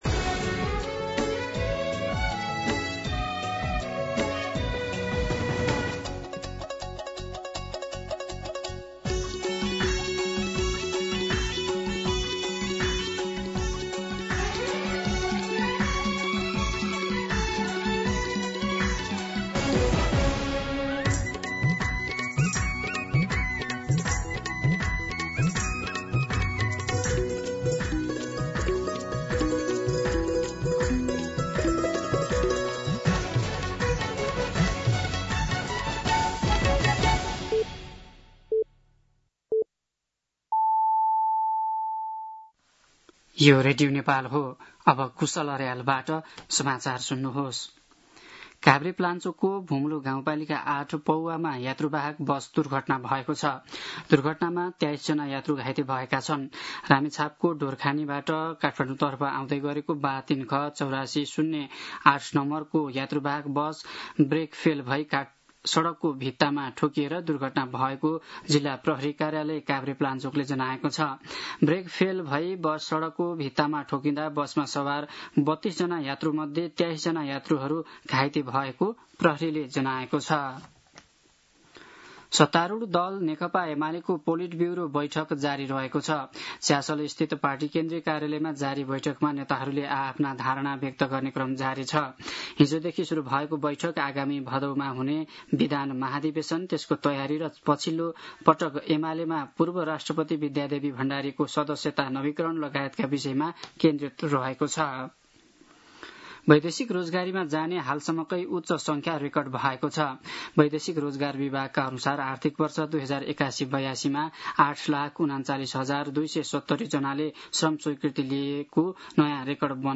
दिउँसो ४ बजेको नेपाली समाचार : ३ साउन , २०८२
4-pm-Nepali-News-2.mp3